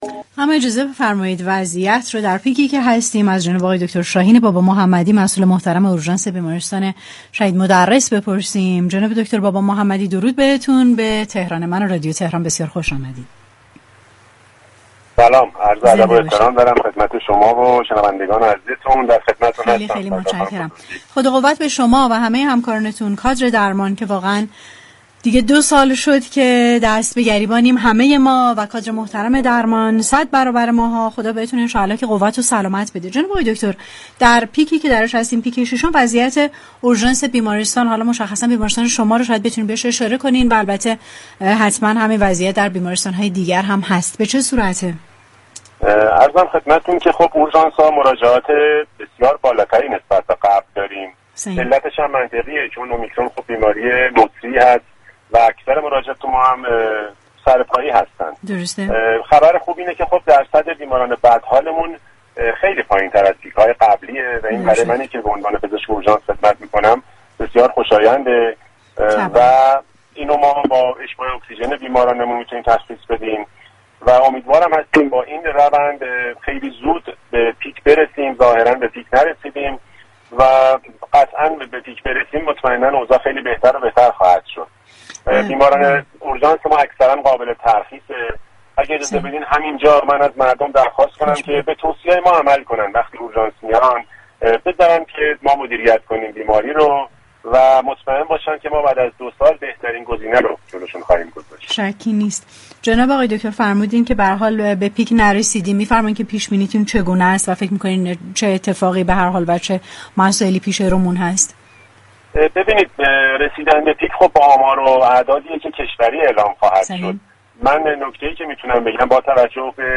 در گفتگو با تهران من رادیو تهران